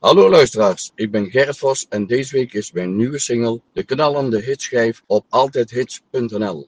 in de uptempo sfeer